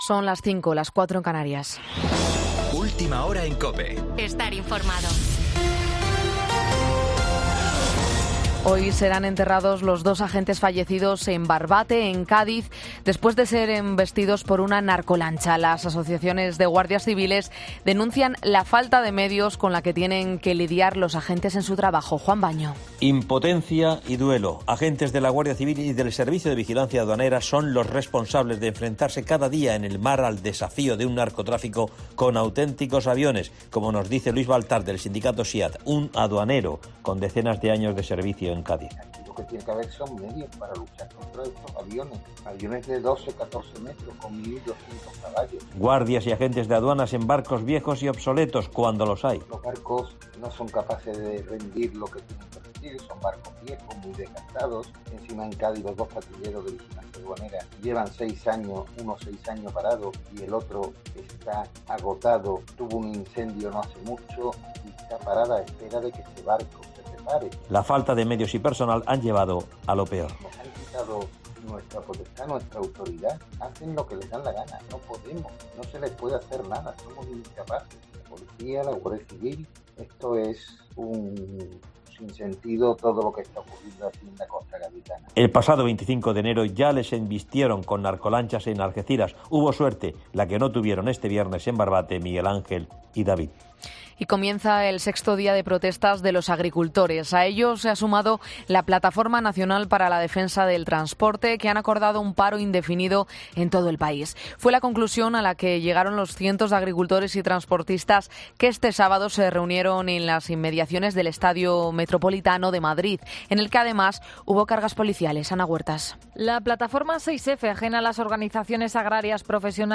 AUDIO: Boletín 05.00 horas del 11 de febrero de 2024